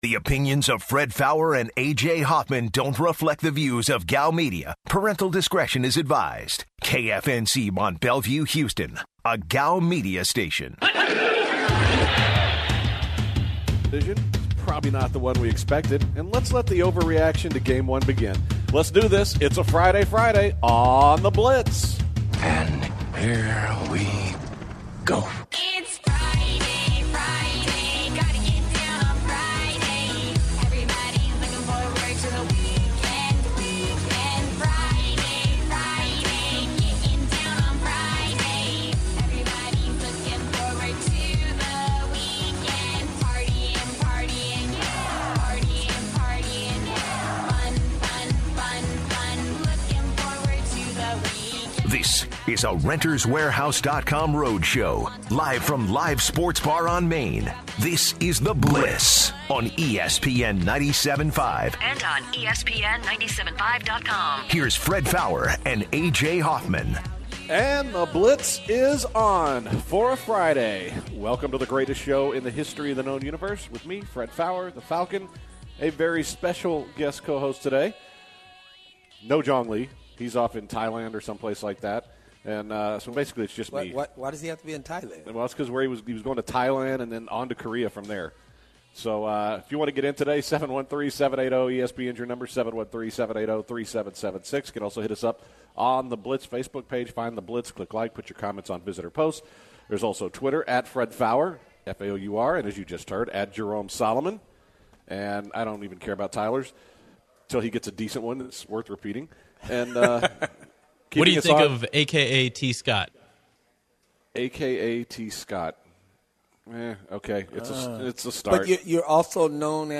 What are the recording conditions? kick off the first hour of The Blitz from Live Sports Bar & Grill and start the show talking about the Big 12 bringing back a championship game